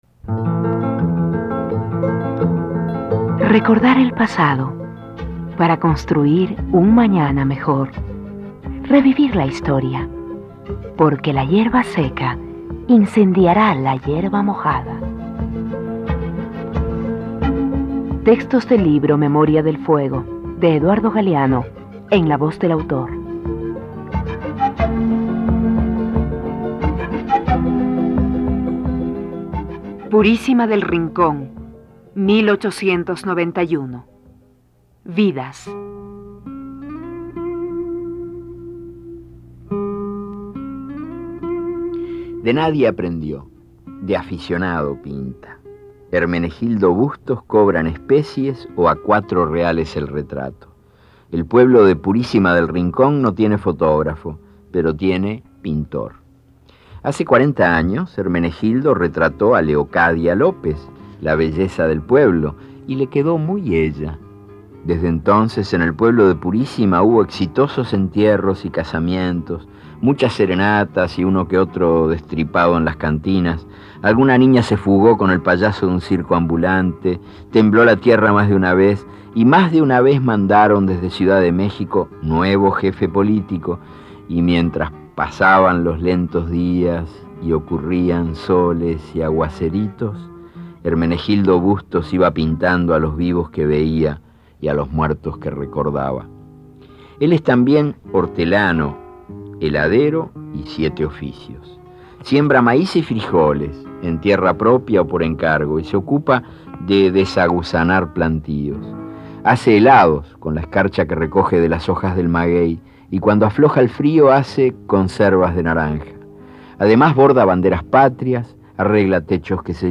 Relato en prosa perteneciente a Memoria del fuego (II). Las caras y las máscaras, segundo libro de la trilogía del escritor Eduardo Galeano que narra la historia de América Latina hasta el siglo XX.
Este archivo de sonido ofrece la lectura del texto en la voz de su autor.
Eduardo Galeano_Vidas.mp3